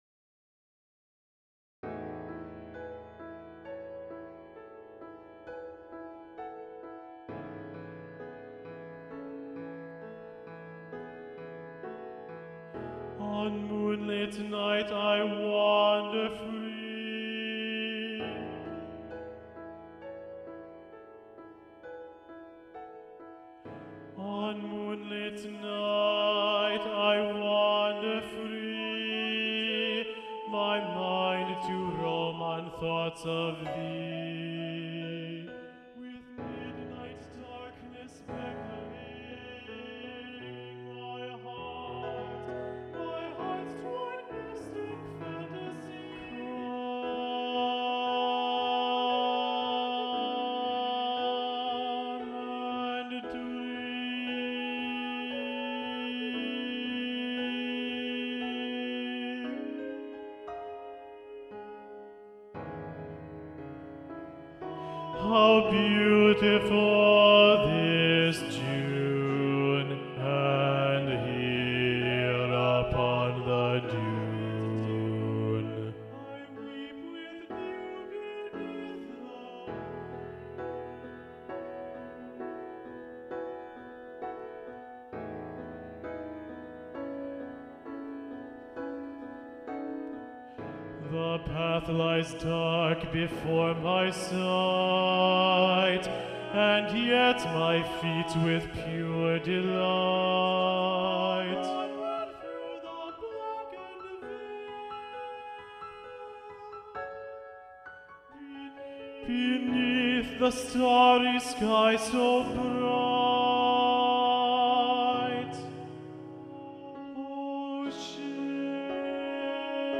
Bass 2
Ballade-to-the-Moon-Bass-2-Predominant-Daniel-Elder.mp3